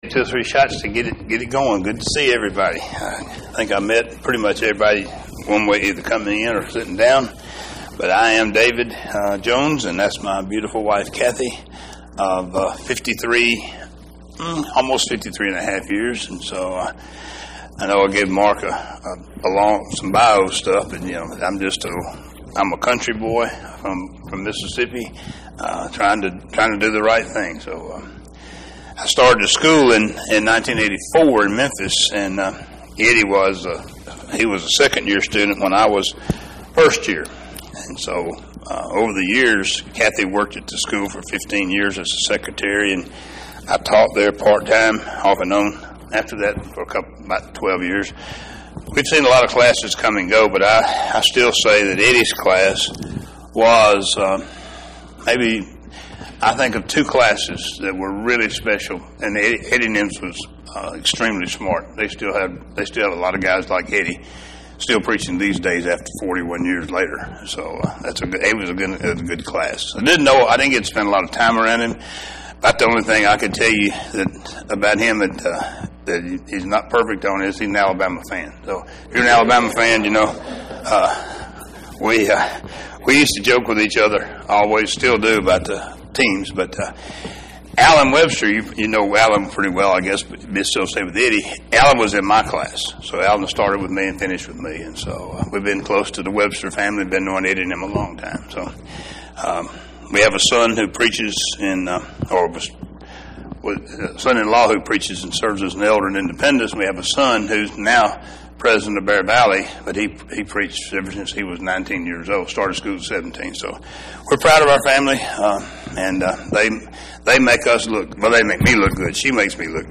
Bible Study – Numbers 20:1-4